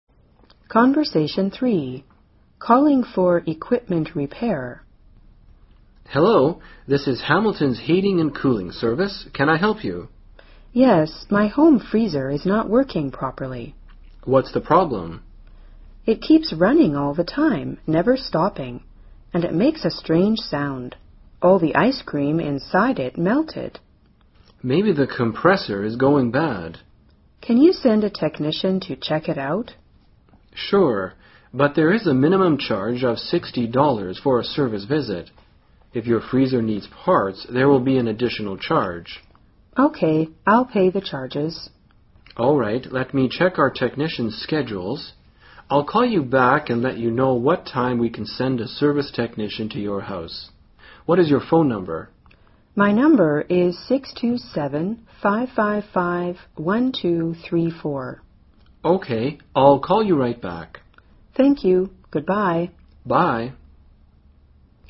【对话3：打电话修理电器】